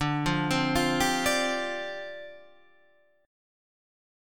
D Minor 11th